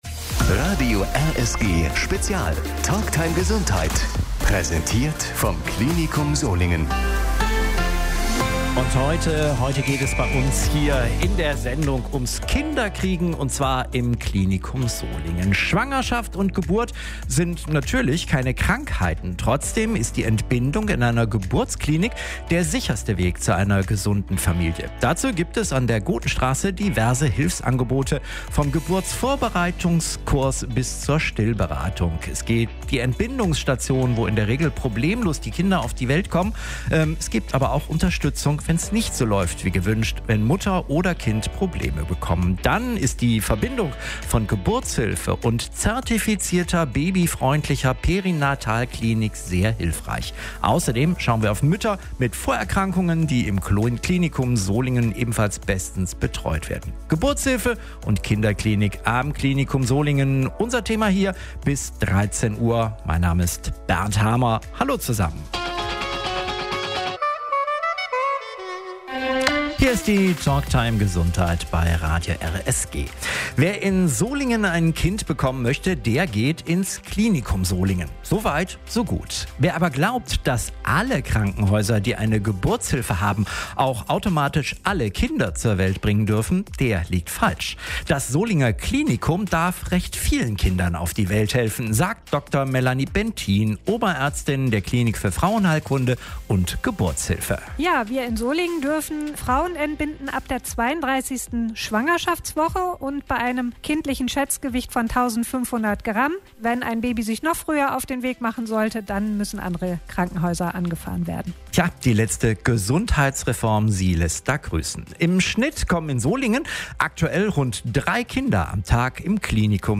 Um Schwangerschaft und Geburt ging es am 20. Dezember in der Radiosprechstunde.